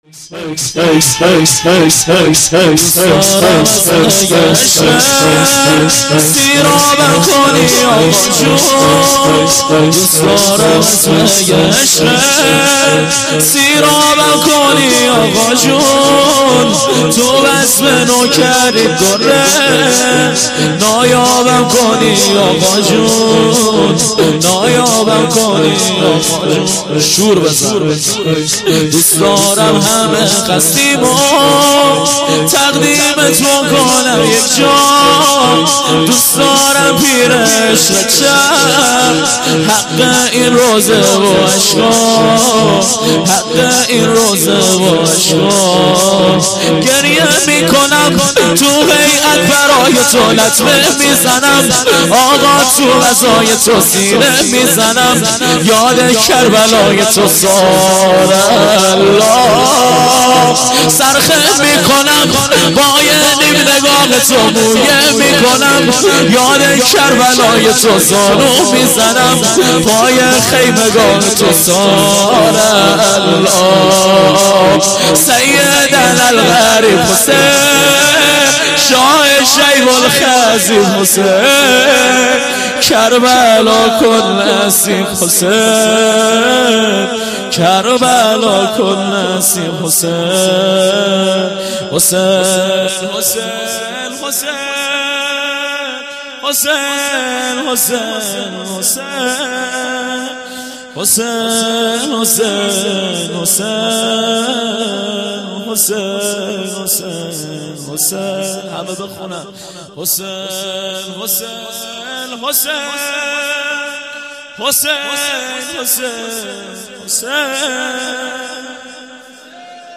شور - دوست دارم از مي عشقت